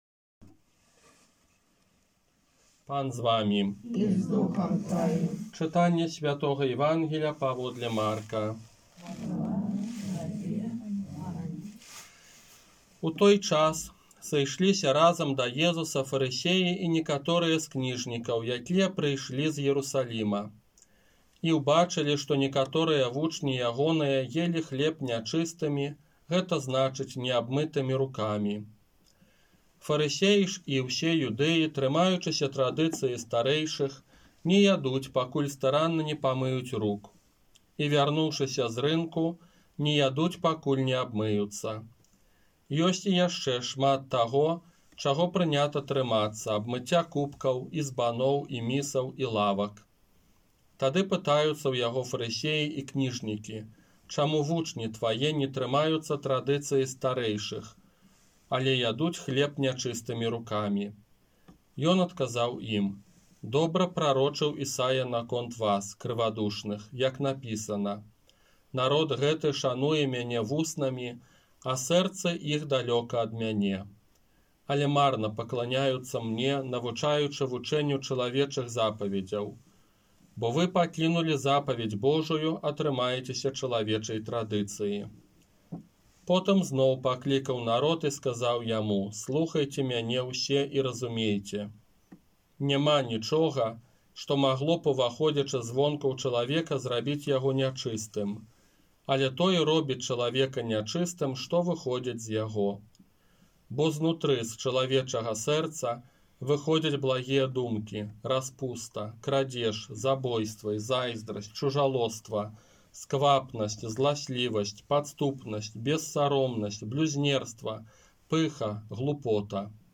Казанне на дваццаць другую звычайную нядзелю 29 жніўня 2021 года